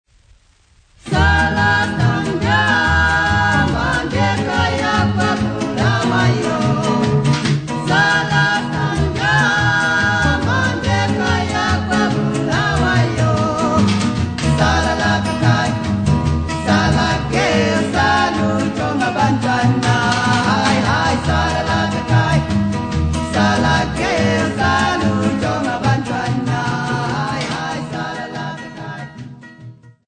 Popular music--Africa
sound recording-musical
Jive song with guitar and drum accompaniment